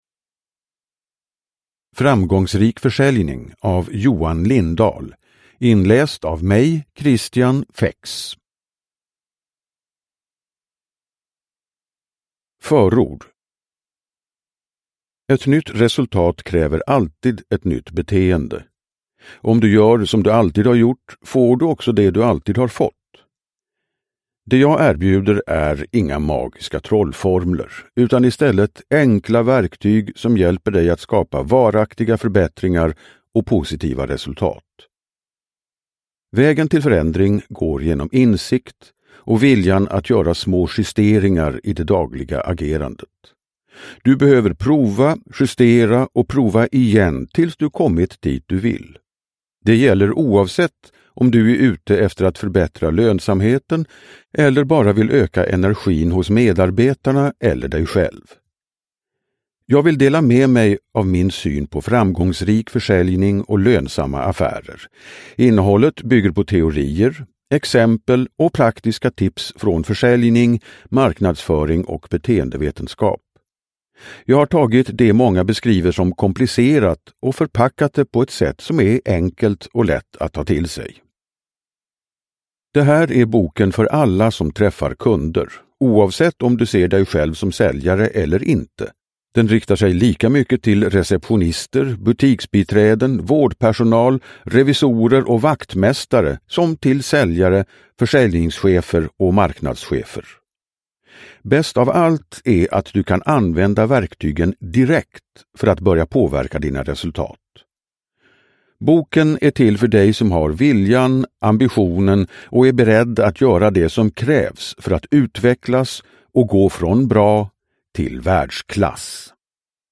Framgångsrik försäljning : från bra till världsklass – Ljudbok – Laddas ner